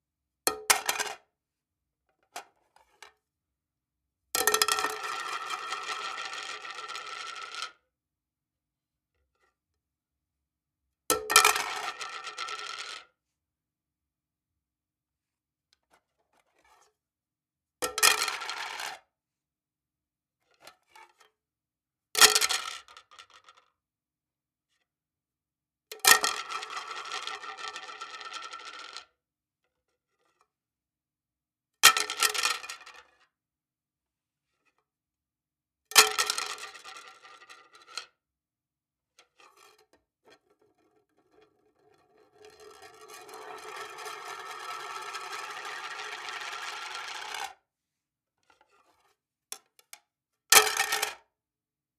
Plate Plastic Ceramic Dropped On Floor Pack
Breaking Breaking-Glass Broken Ceramic Cleaning Clink Clinking Crack sound effect free sound royalty free Music